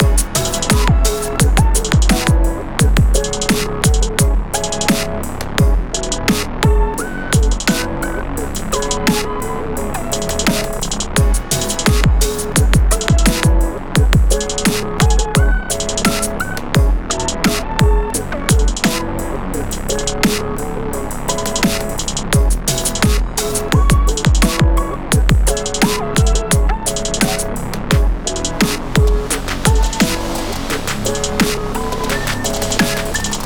Music - Song Key
Db Minor